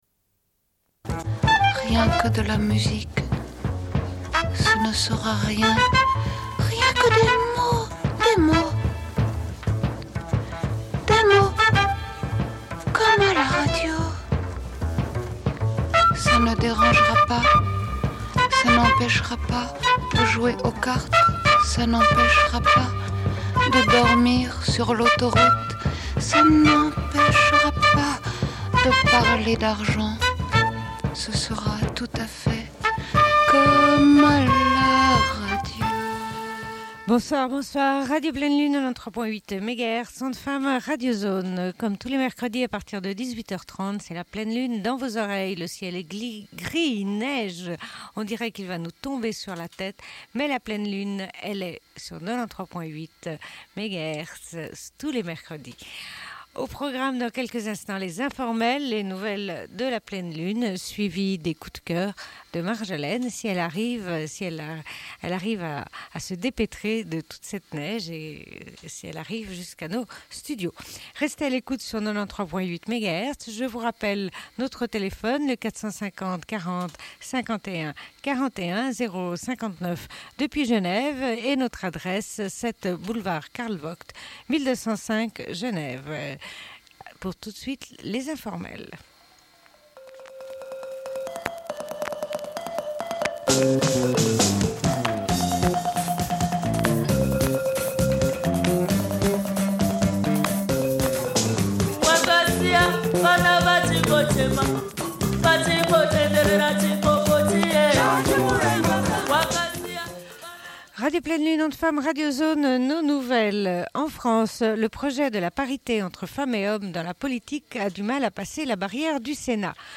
Bulletin d'information de Radio Pleine Lune du 16.12.1998 - Archives contestataires
Une cassette audio, face B